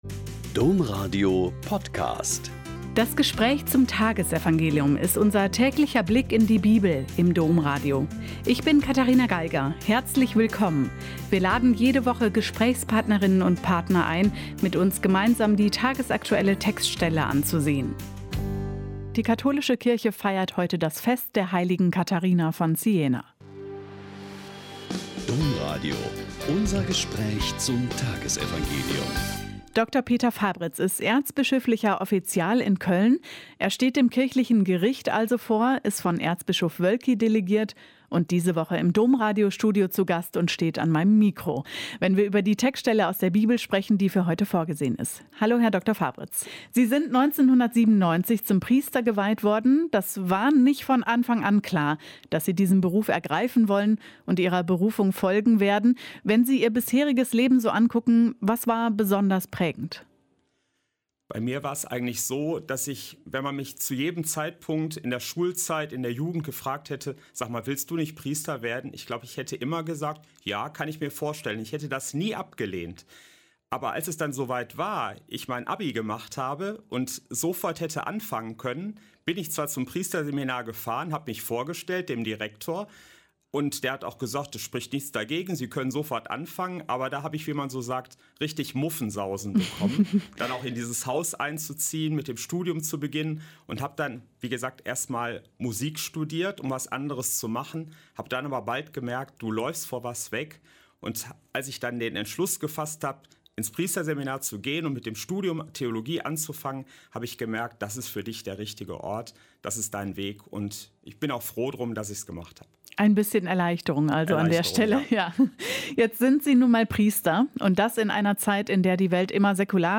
Mt 11,25-30 - Gespräch